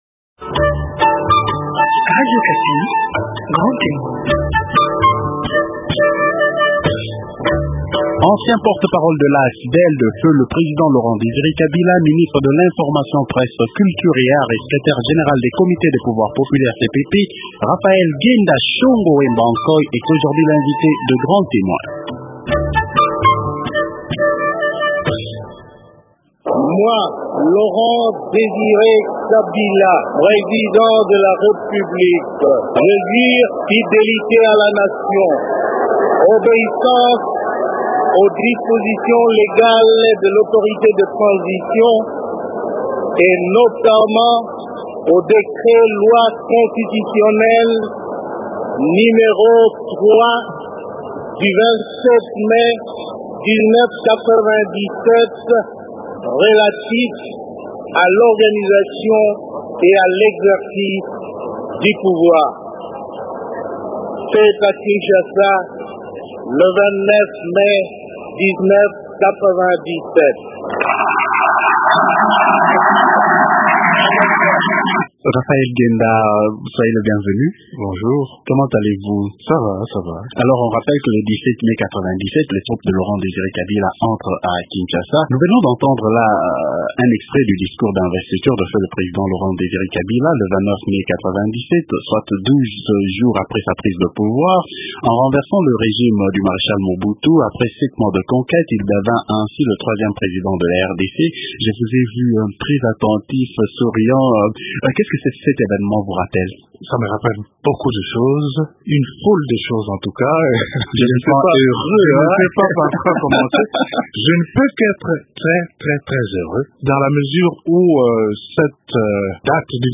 Raphaël Ghenda Shongo Wembakoy, est un ancien porte- parole de l’AFDL de feu le président Laurent Désiré Kabila, ministre de l’information presse, culture et art et secrétaire général des comités des pouvoirs populaires, CPP.